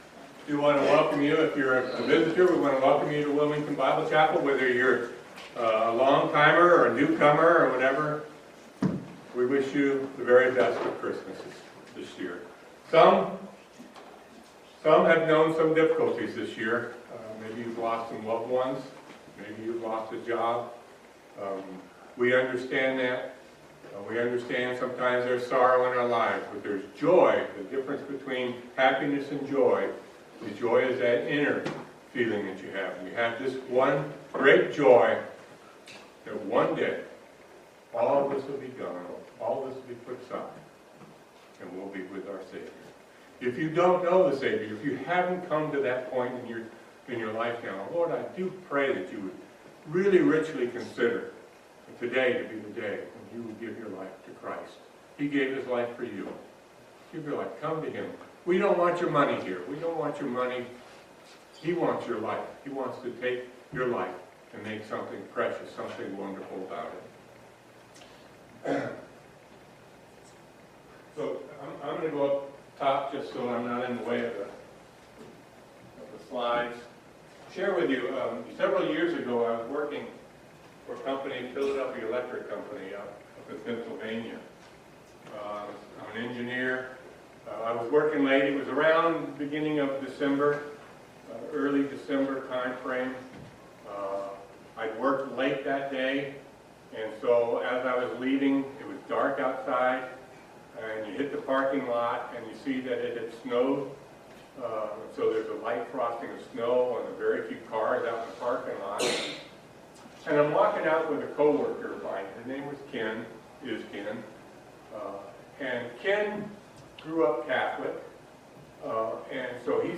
Matthew 1:18-20 Service Type: Family Bible Hour Jesus’ virgin birth makes Him the perfect sinless sacrifice.